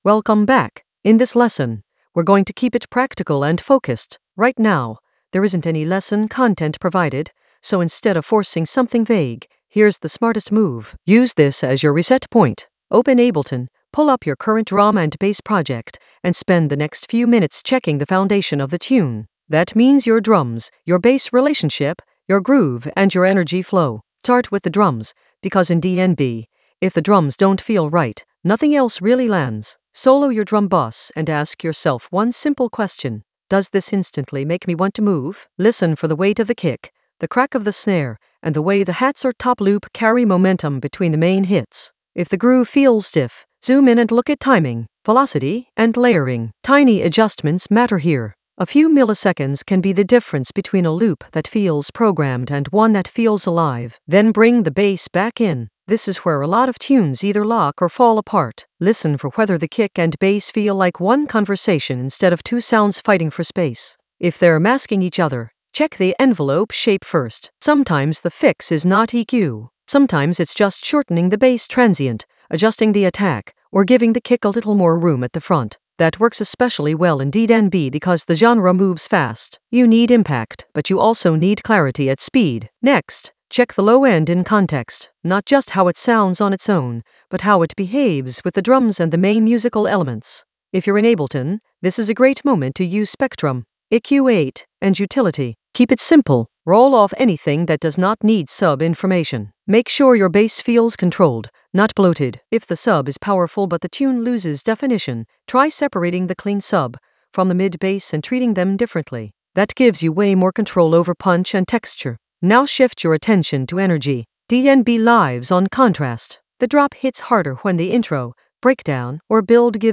An AI-generated beginner Ableton lesson focused on Helter Skelter Style Rave FX in the FX area of drum and bass production.
Narrated lesson audio
The voice track includes the tutorial plus extra teacher commentary.